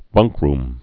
(bŭngkrm, -rm)